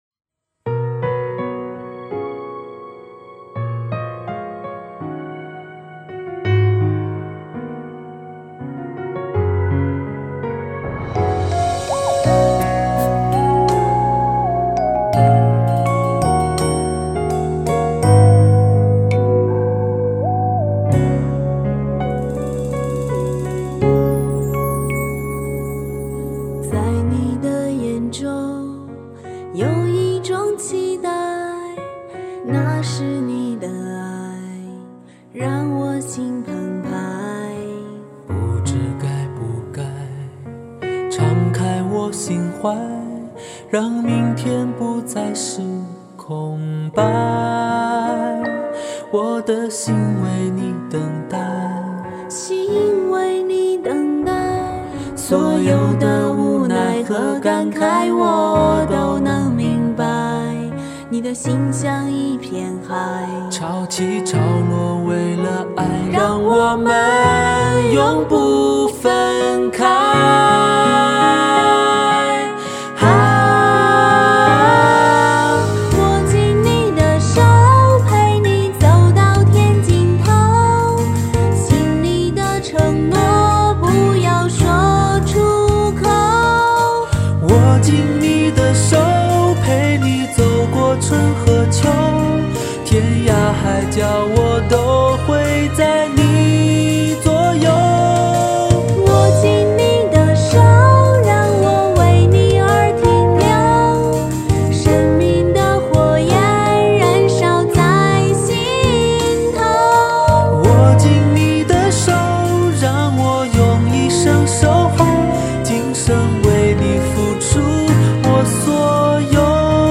深情对唱飞向彩虹之巅。